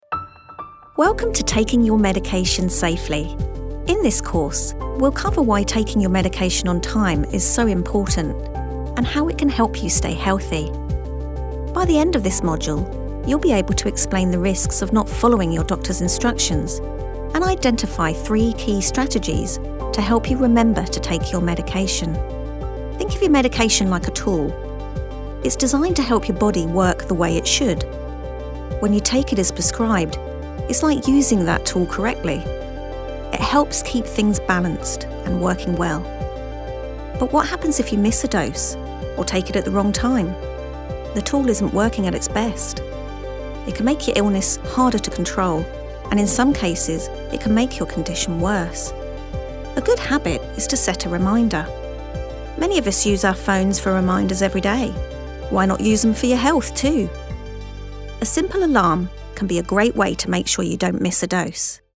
Female
Yng Adult (18-29), Adult (30-50)
Her calm, friendly, and articulate tone is easily understood by global audiences, making her ideal for corporate narration, e-learning, and explainer content.
E-Learning
E-Learning Example 2
Words that describe my voice are Calm, Authoritative, Conversational.